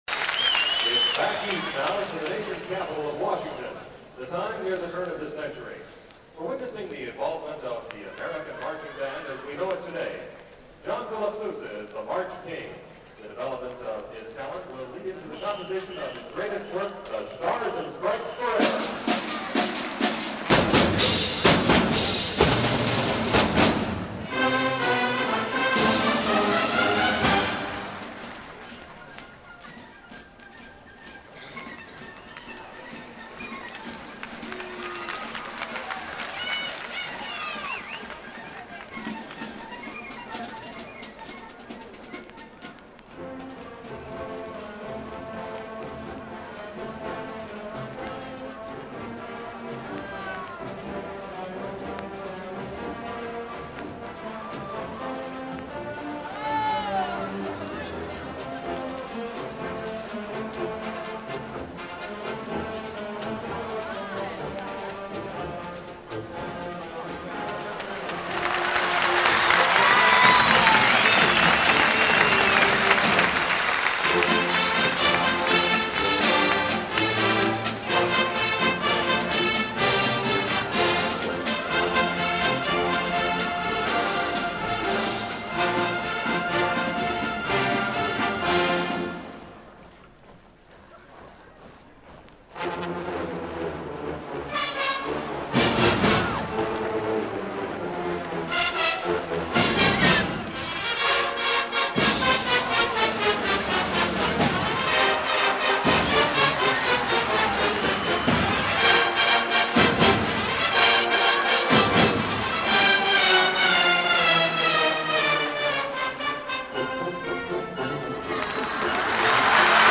What made the band different was that they had total balance, including an outrageous woodwind sound.
Stars and Stripes Live performance 1975 featuring Sousaphones on the piccolo oblagato (3.7 MB)